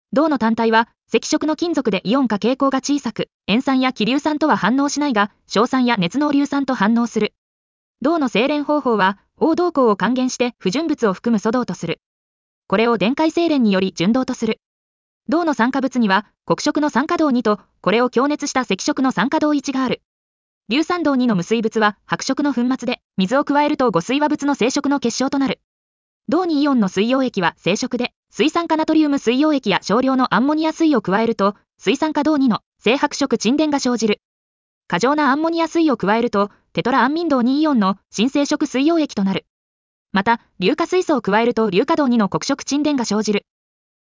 • 耳たこ音読では音声ファイルを再生して要点を音読します。
ナレーション 音読さん